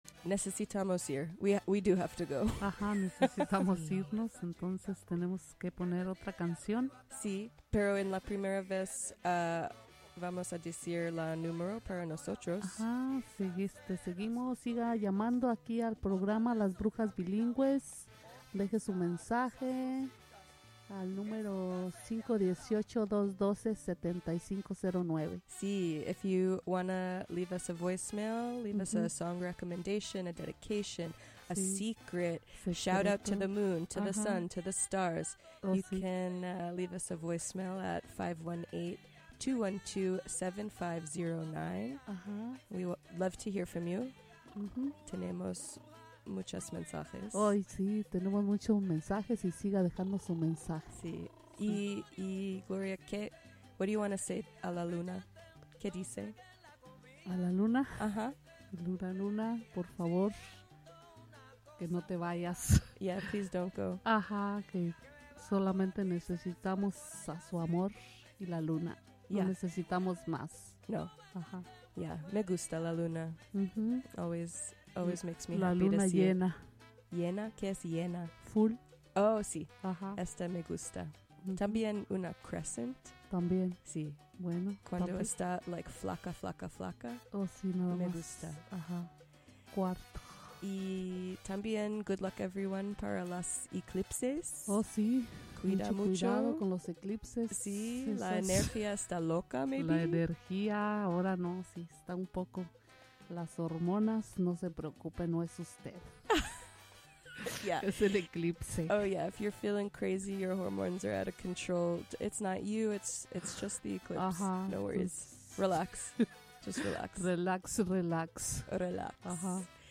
The Bound By Books crew discusses books they love, books they're currently reading, and other teen media and literary topics.